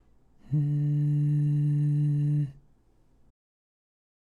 ※喉頭は通常位置のパーの声(ん)